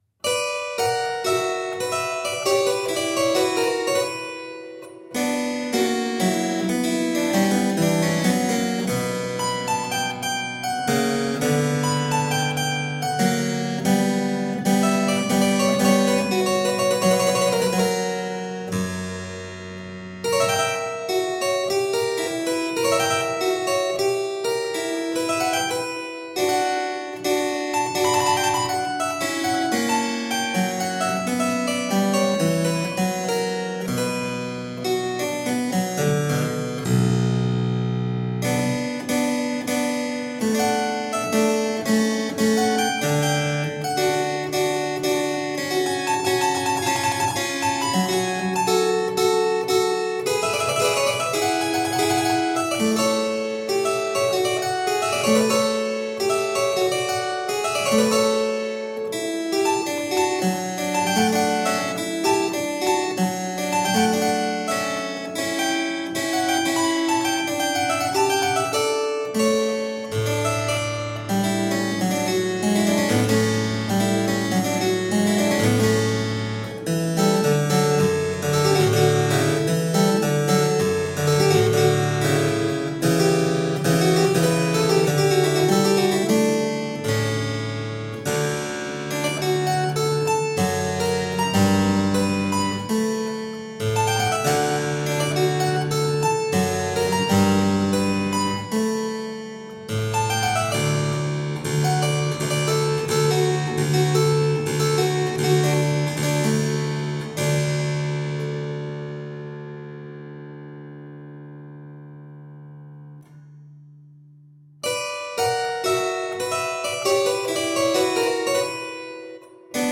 Delicious harpsichord rarities.
Classical, Classical Period, Instrumental, Harpsichord